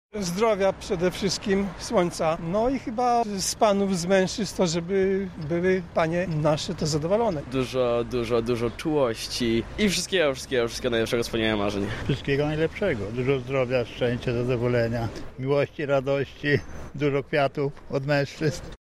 Posłuchajcie czego mieszkańcy Lublina życzą kobietom z okazji ich święta.